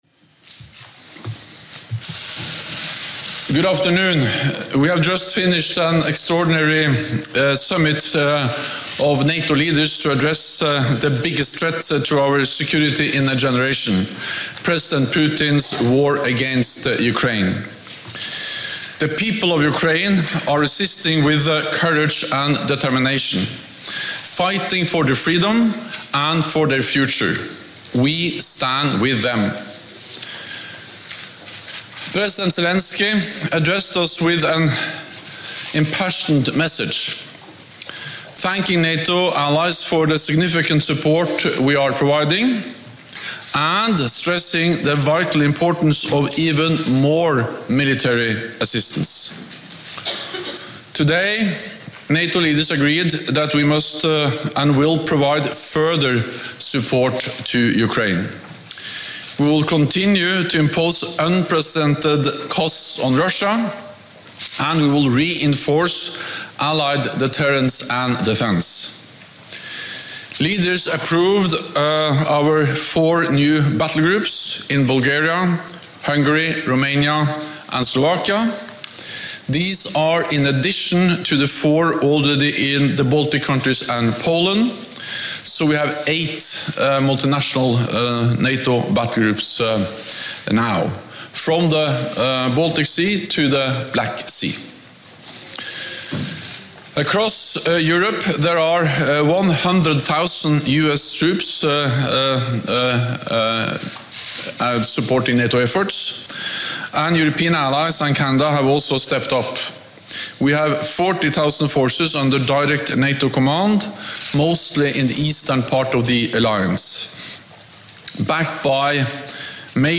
Jens Stoltenberg - Presser Announcing New NATO Measuresin Support of Ukraine Military Campaign Against Russian Aggression (transcript-audio-video)